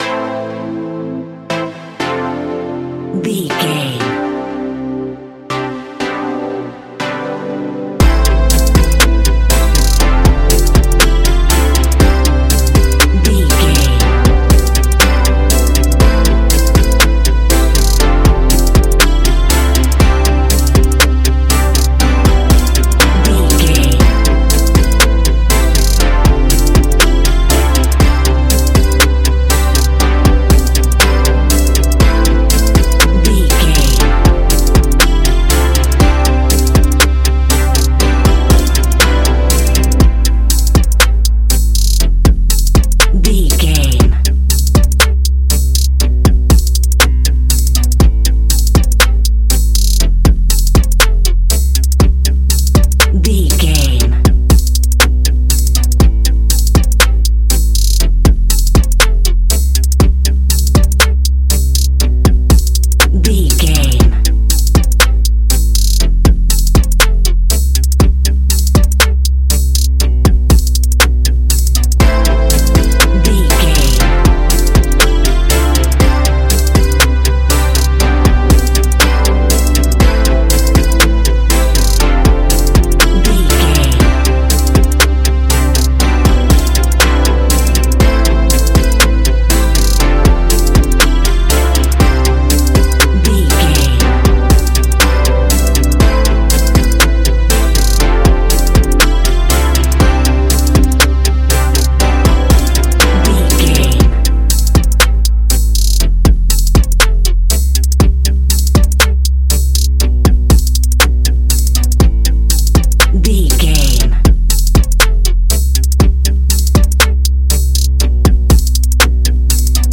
Ionian/Major
A♭
ambient
electronic
new age
chill out
downtempo
pads
drone